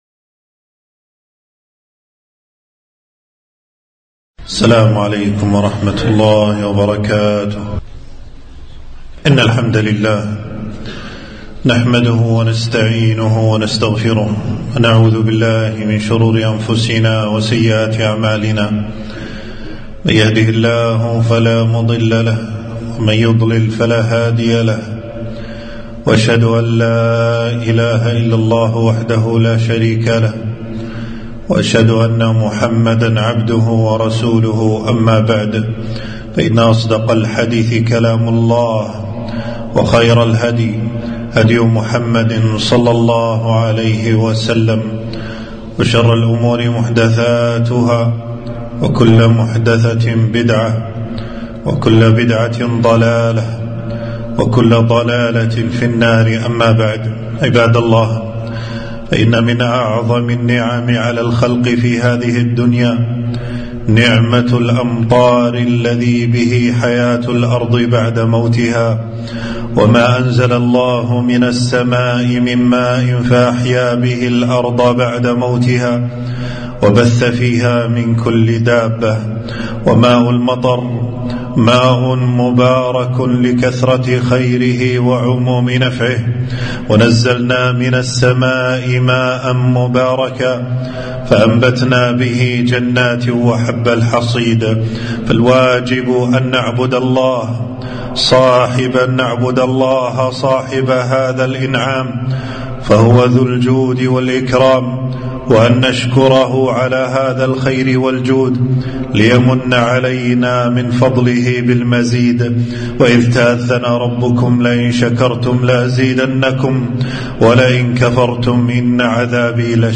خطبة - نعمة المطر والسنن الواردة عند نزوله